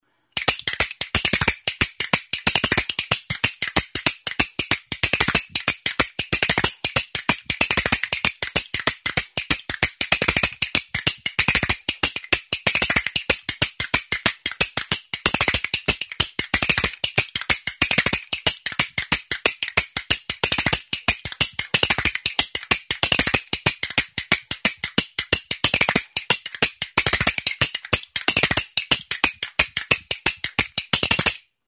Joaldia kaskainetekin.
Grabado con este instrumento.
CASTAÑUELAS; KASKAINETAK
Idiófonos -> Golpeados -> Indirectamente